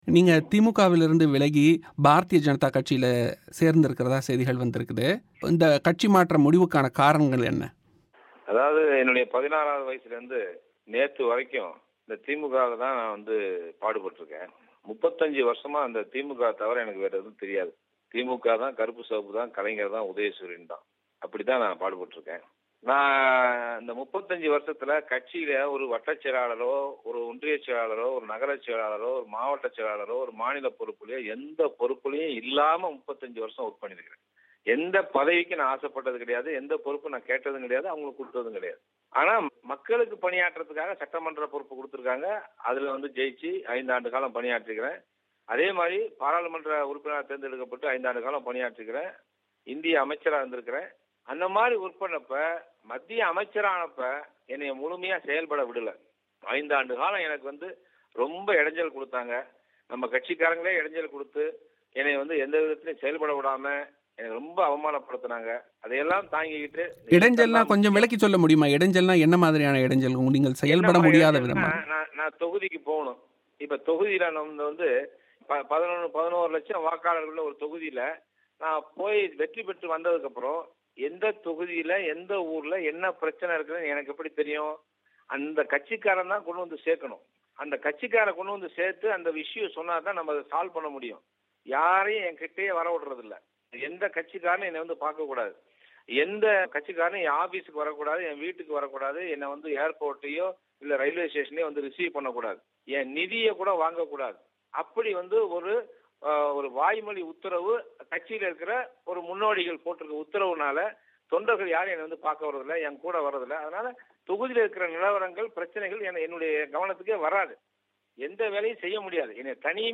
திமுகவில் ஓரங்கட்டப்பட்டேன் - பாஜகவில் இணைந்துள்ள நெப்போலியன் பேட்டி